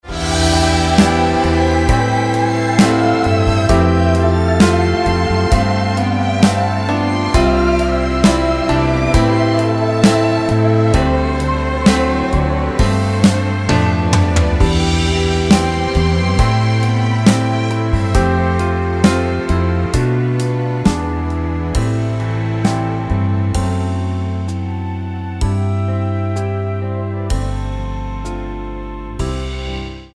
Tonart:Bb-C mit Chor
Die besten Playbacks Instrumentals und Karaoke Versionen .